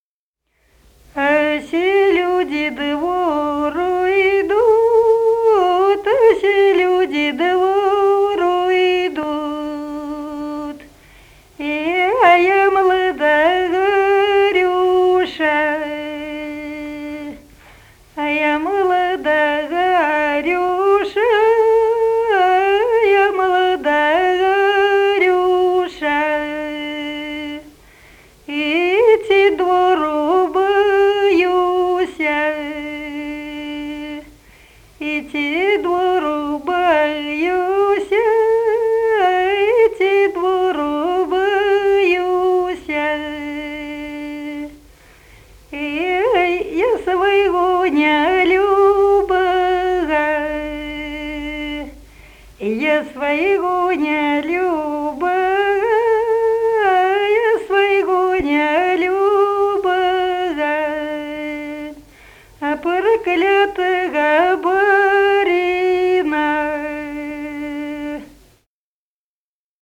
Народные песни Смоленской области
«А все люди двору идут» (жнивная).